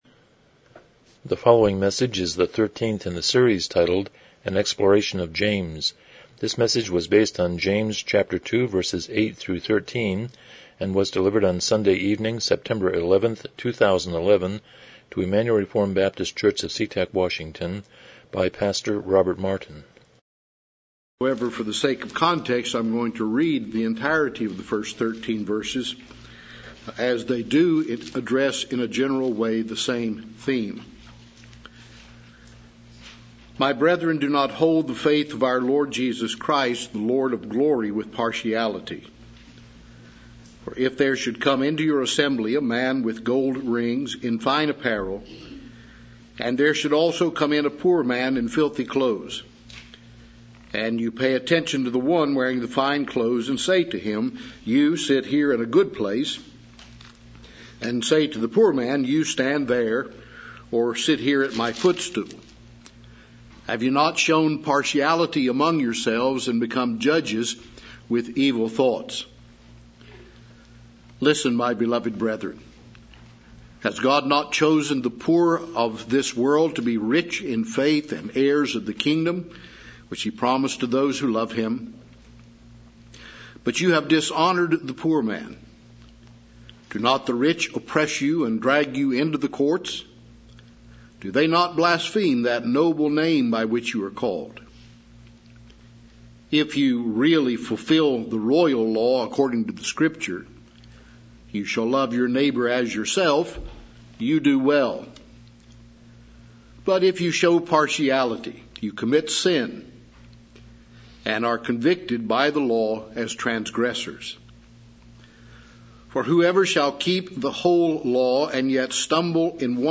James 2:8-13 Service Type: Evening Worship « 150 Romans 14:1-4 129 Chapter 25.5-6